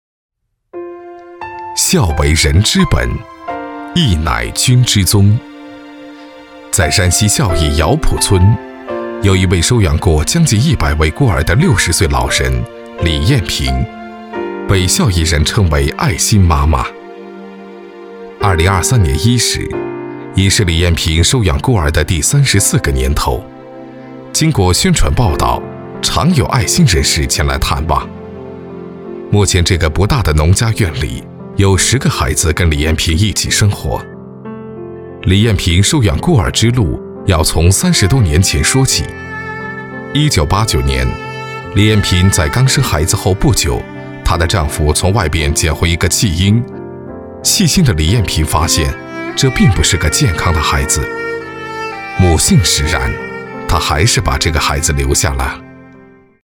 男国语217